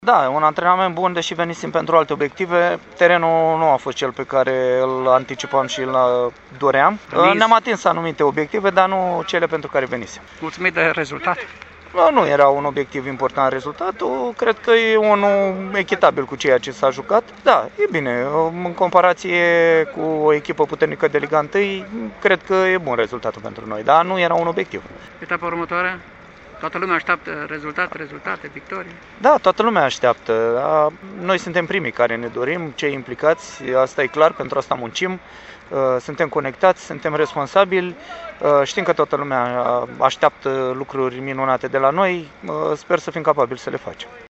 La finalul partidei disputate la Simeria,